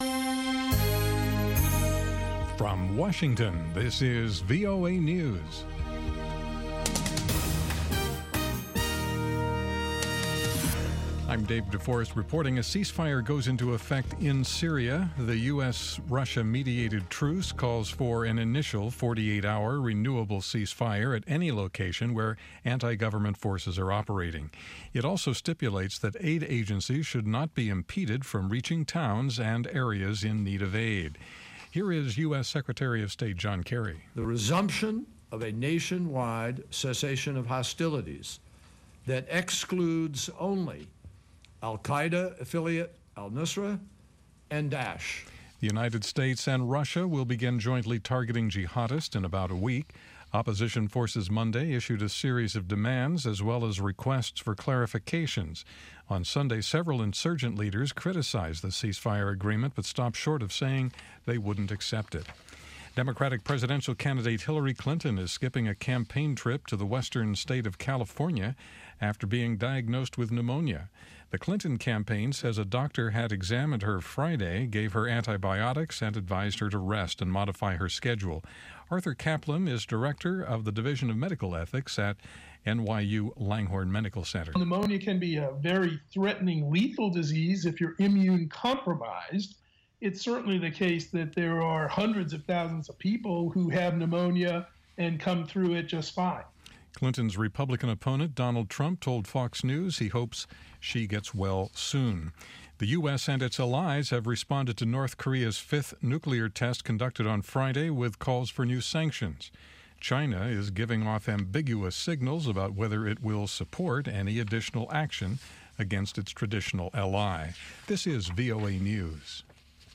2000 UTC Newscast in English for September 12